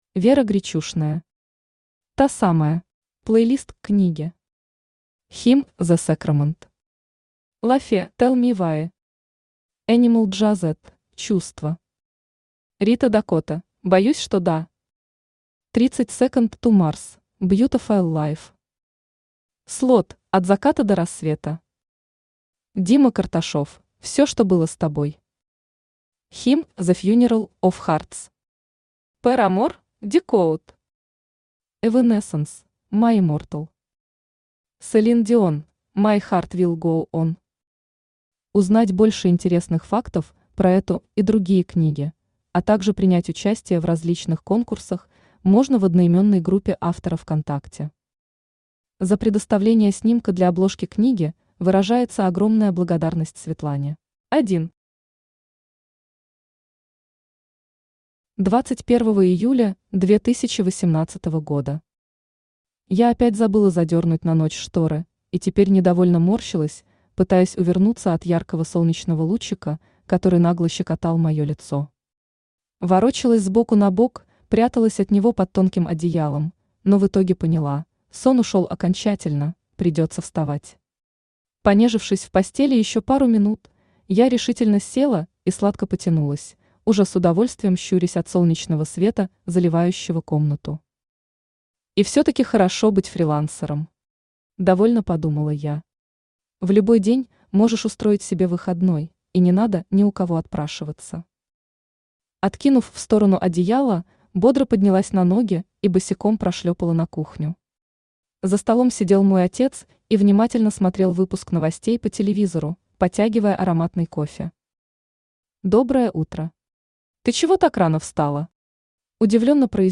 Аудиокнига Та самая | Библиотека аудиокниг
Aудиокнига Та самая Автор Вера Денисовна Гречушная Читает аудиокнигу Авточтец ЛитРес.